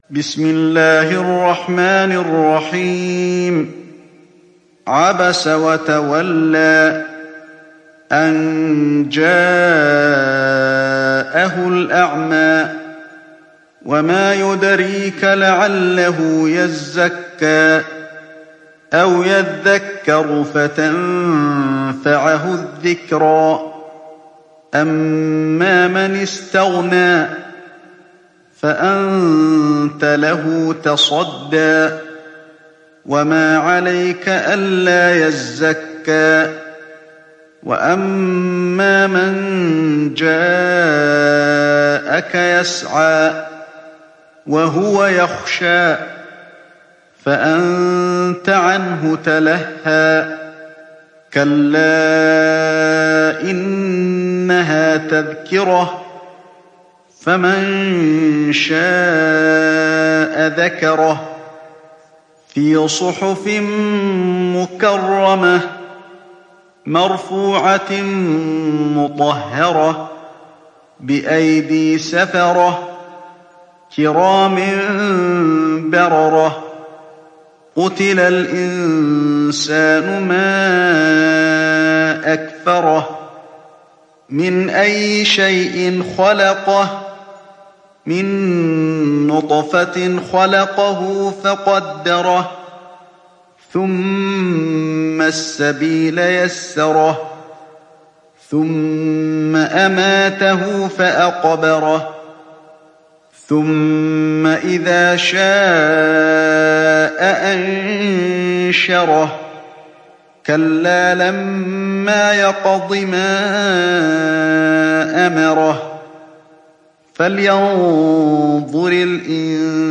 Hafs dari Asim